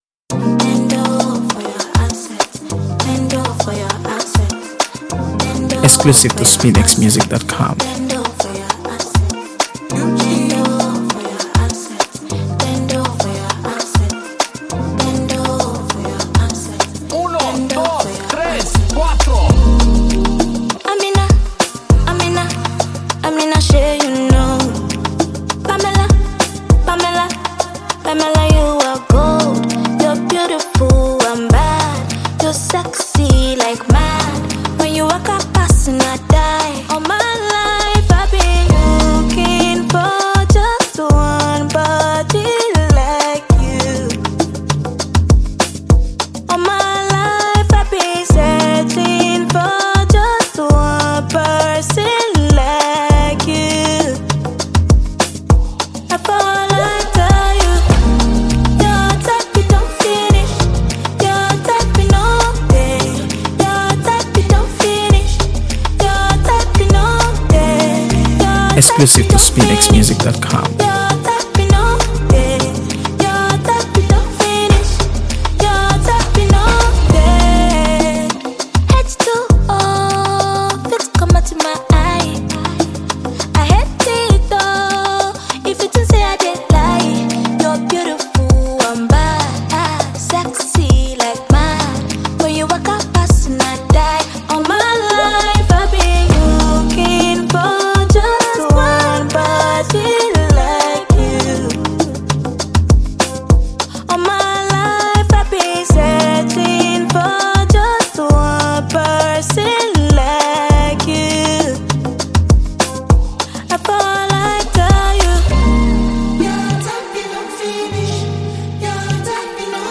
AfroBeats | AfroBeats songs